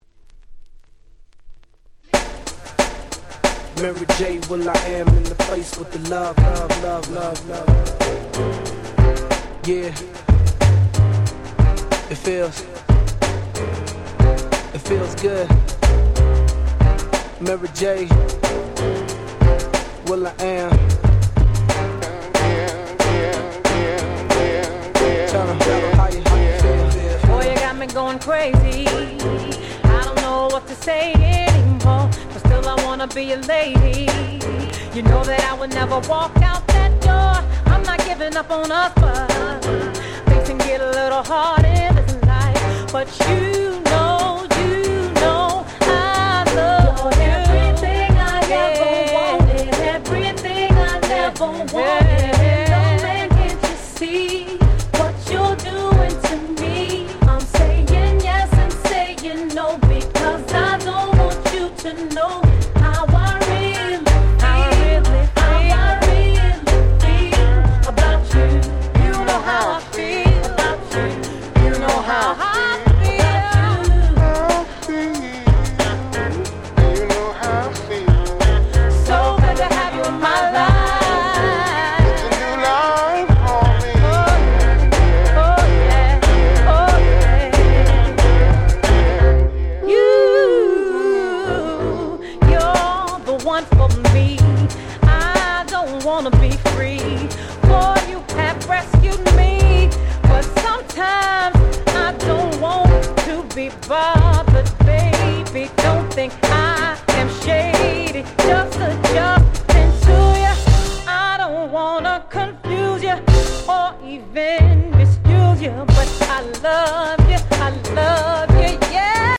05' Super Nice R&B !!